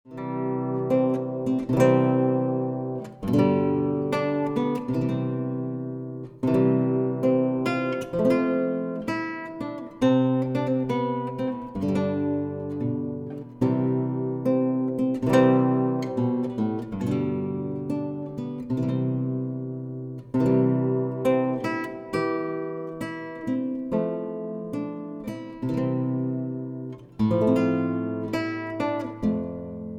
Classical Guitar The Bridal Chorus
Guitarist30s.wav